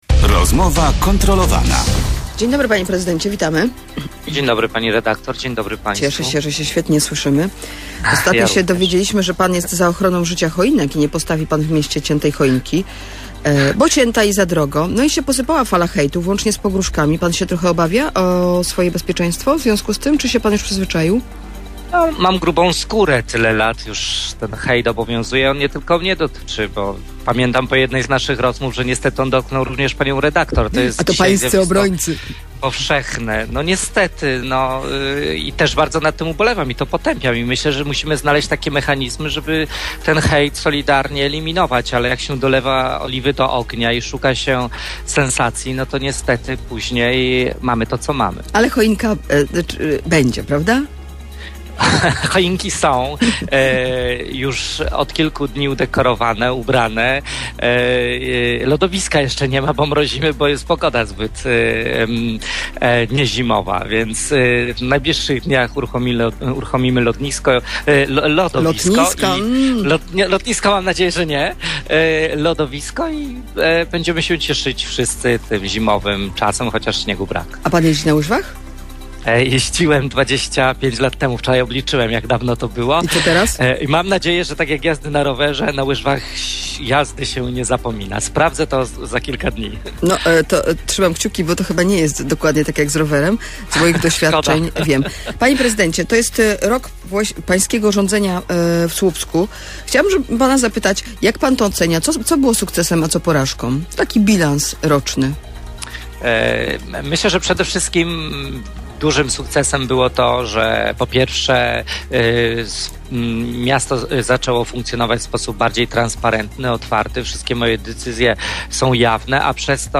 Hejt, podsumowanie roku i znikające połączenia kolejowe. Podczas Rozmowy Kontrolowanej Robert Biedroń To niektóre z tematów poruszonych podczas Rozmowy Kontrolowanej, której gościem był Robert Biedroń.